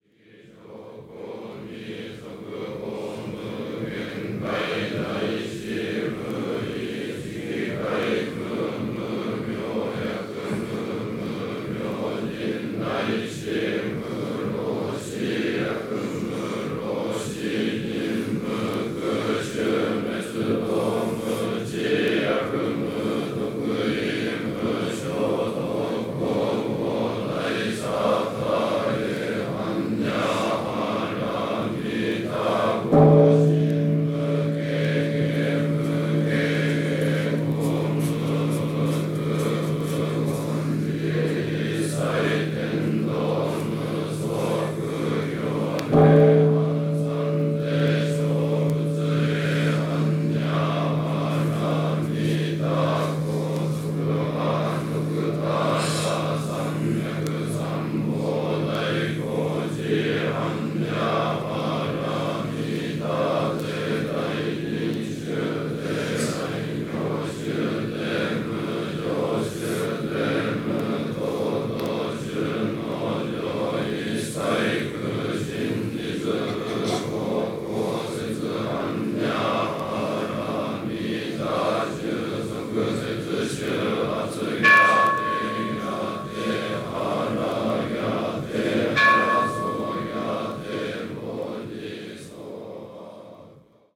シリアスさが収められた２枚組。